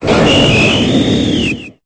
Cri de Silvallié dans sa forme Type : Normal dans Pokémon Épée et Bouclier.